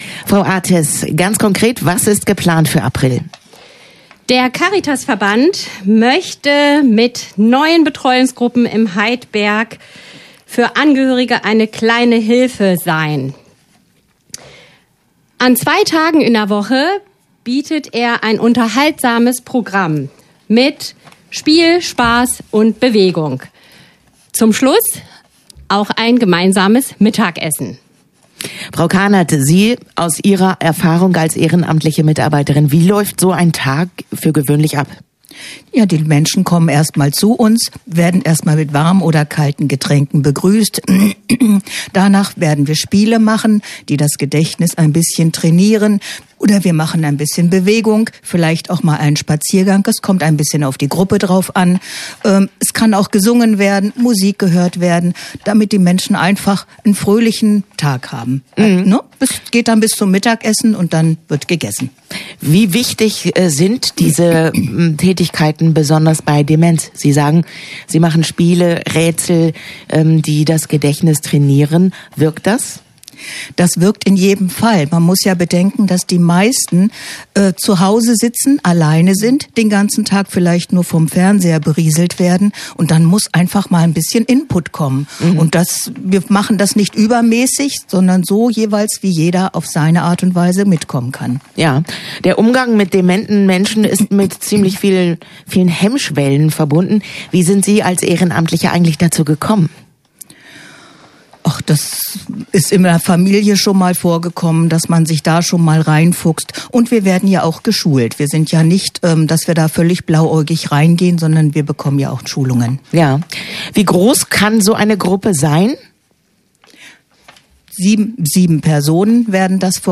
Interview-Caritas_nb.mp3